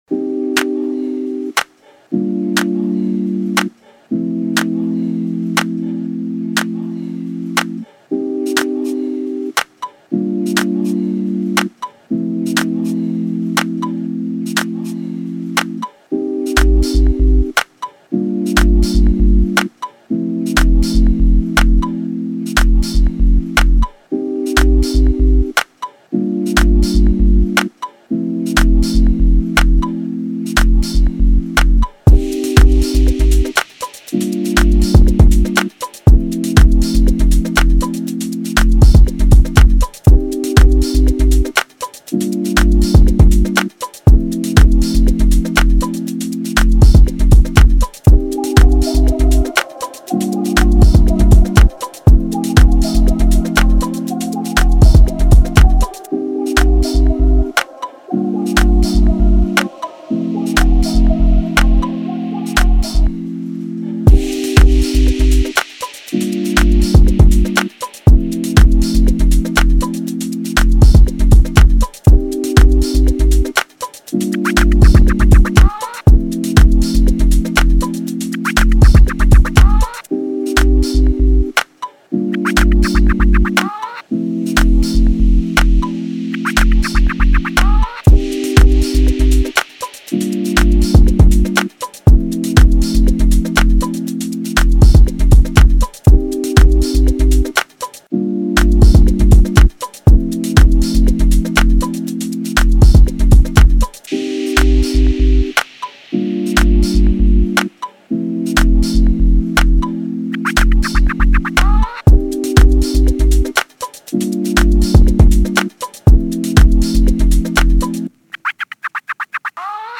official instrumental
R&B Instrumentals